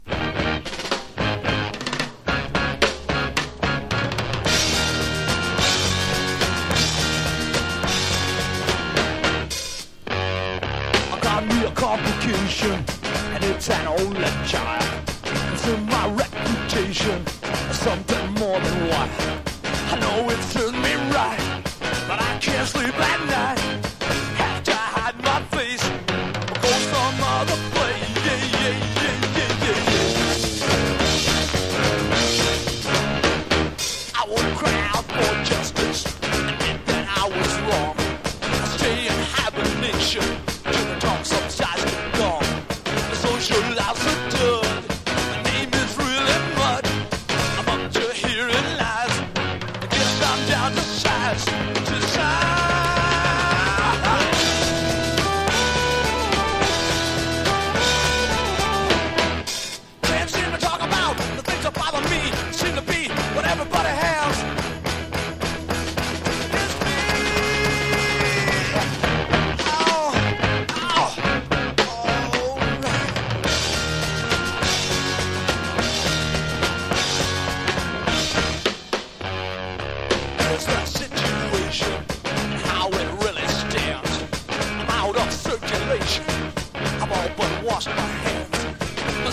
1. 60'S ROCK >